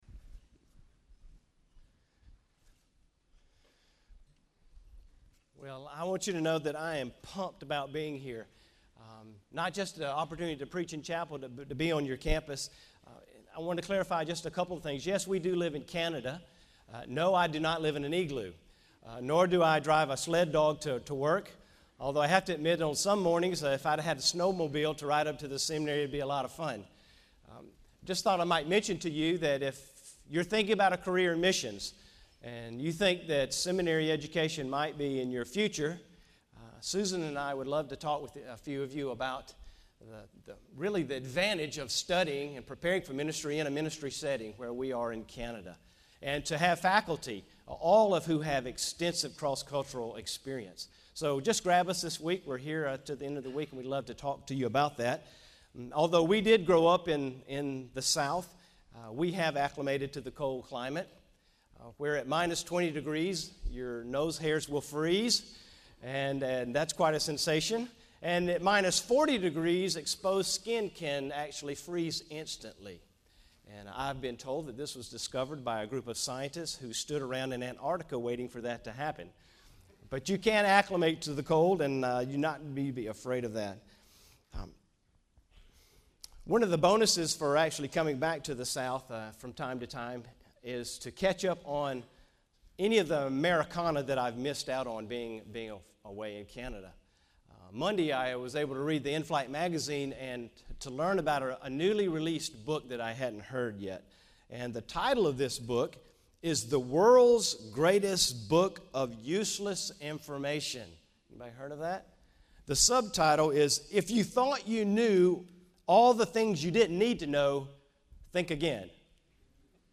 GO Week Chapel